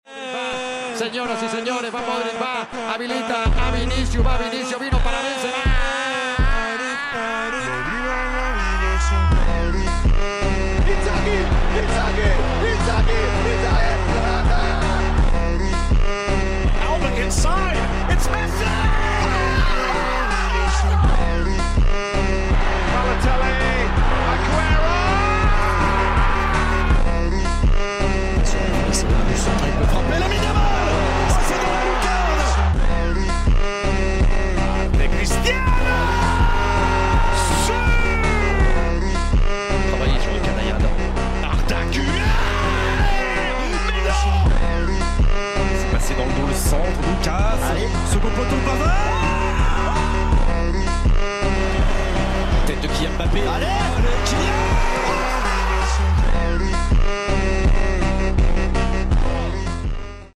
Legendary commentary in football 🔥☠